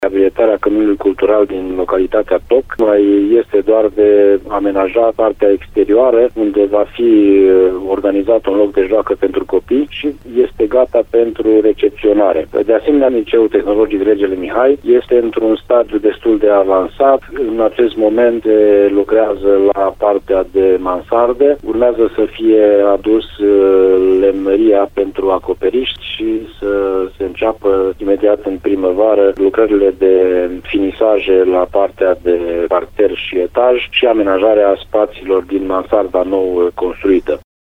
Căminul Cultural din Toc, o lucrare de jumătate de million de euro, este aproape gata, iar lucrările la Liceul Tehnologic Regele Mihai sunt în grafic. Ambele şantiere sunt finanţate cu fonduri europene nerambursabile, spune primarul Ioan Vodicean.